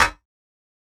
Snare 017.wav